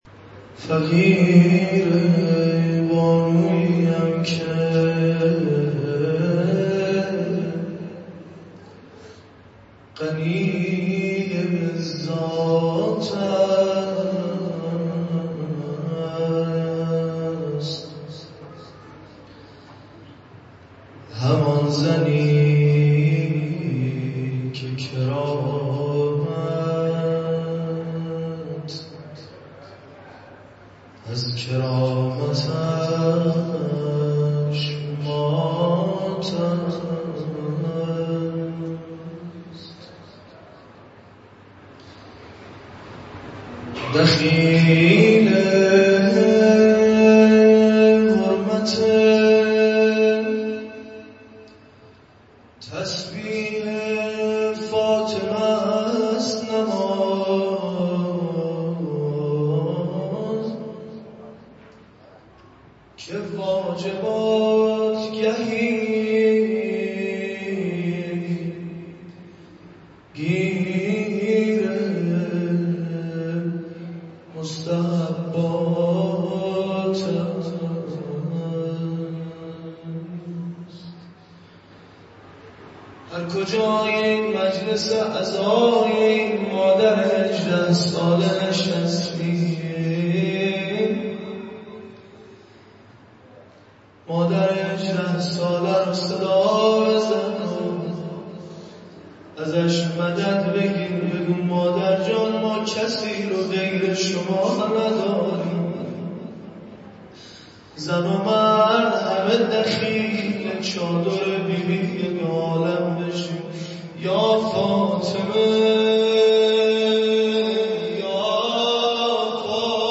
مراسم عزاداری حضرت زهرا (س) به مدت سه شب در سقاخانه حضرت ابالفضل (ع) برگزار گردید.
روضه خوانی